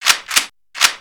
shake.mp3